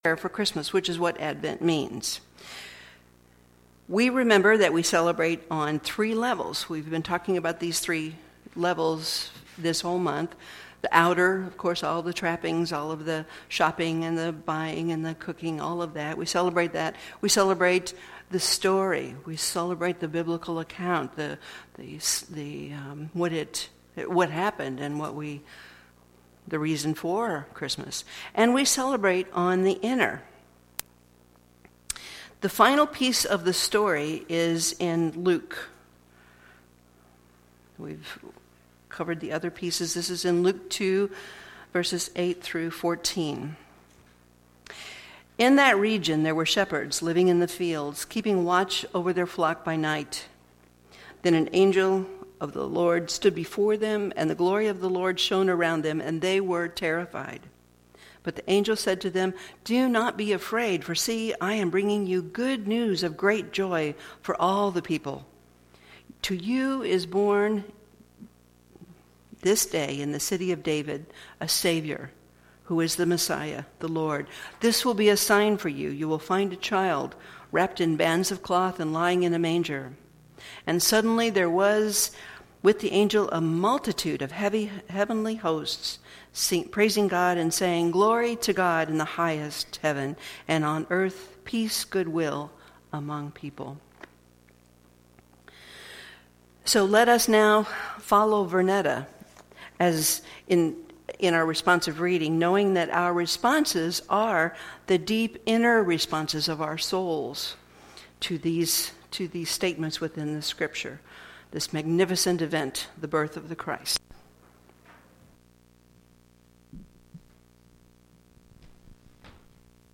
12/20/2015 Christmas Candle Lighting Service
Series: Sermons 2015